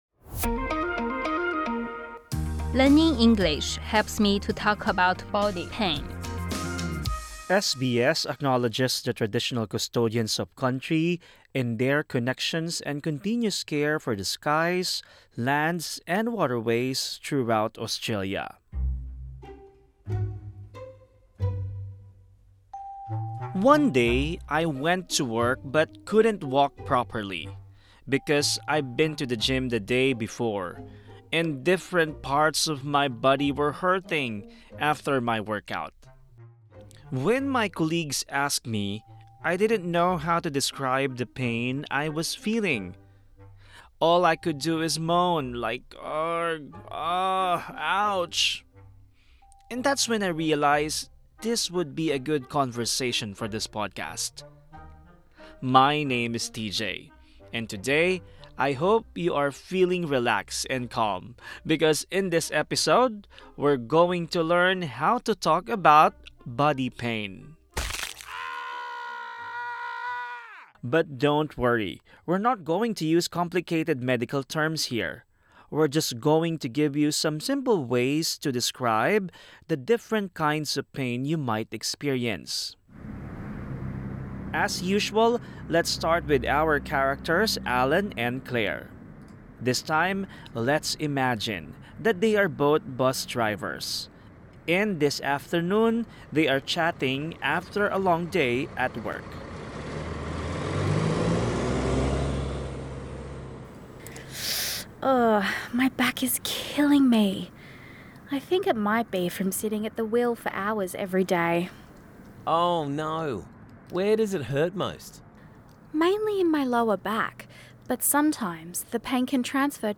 Learn how to describe your body pain. Plus, hear some tips from a physiotherapist if you feel pain.